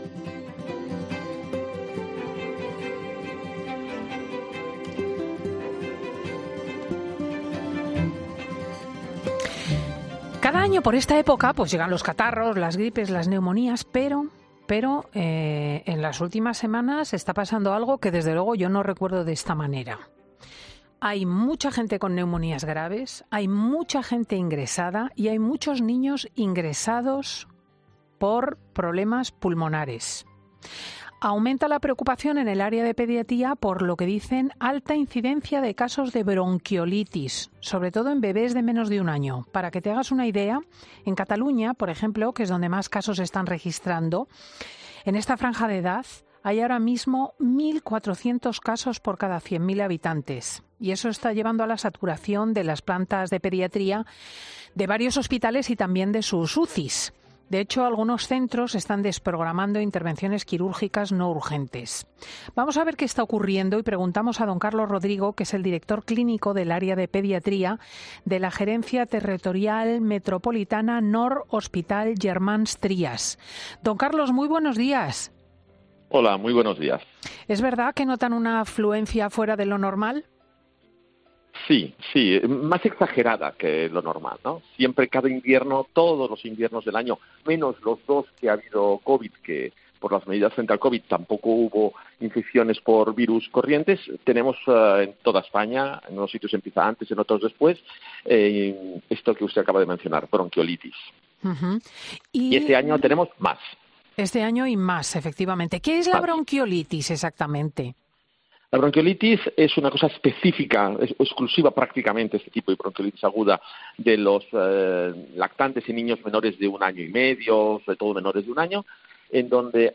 Tras dos años protegiéndonos del COVID se han disparado los casos de bronquiolitis y en 'Fin de Semana' en COPE hablamos con un experto para que nos dé las claves